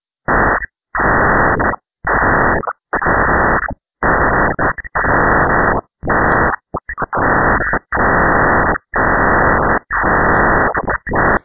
Saturn sound emmisions
Category: Animals/Nature   Right: Personal